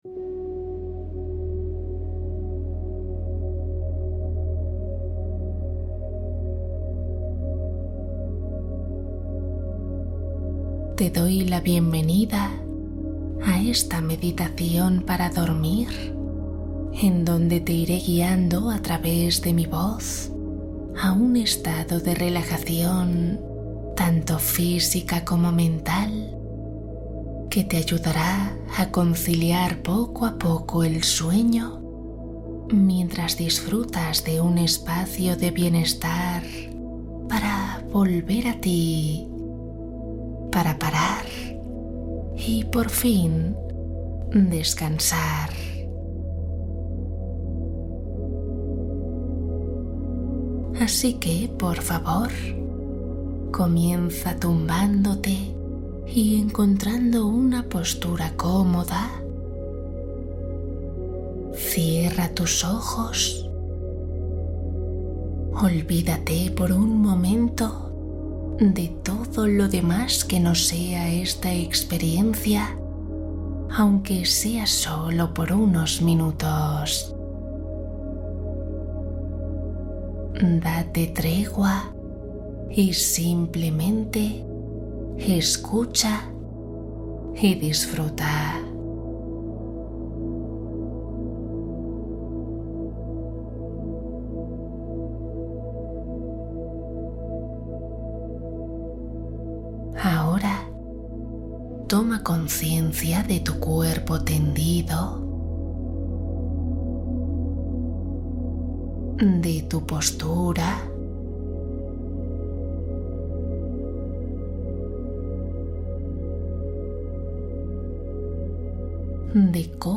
Relato nocturno: historia meditativa para encontrar equilibrio interior